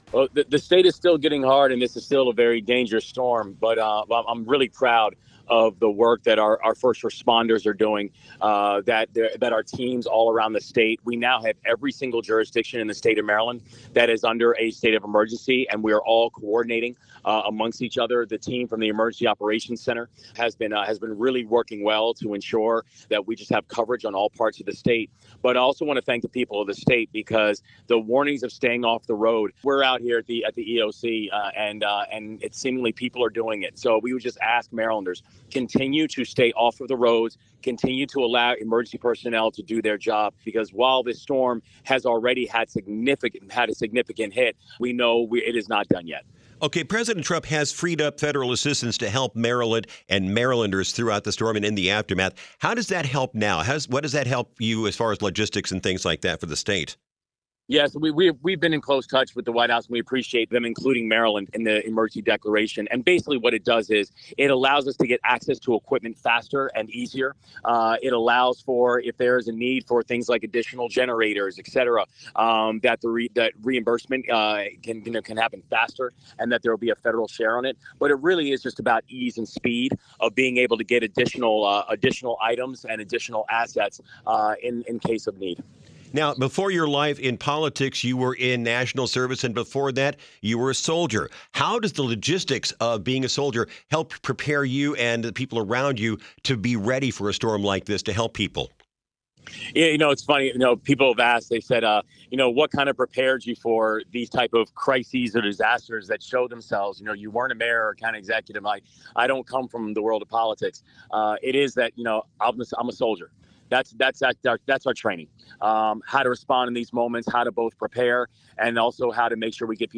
March 12, 2026 | Maryland Gov. Wes Moore speaks with WTOP about the state's snow clearing crews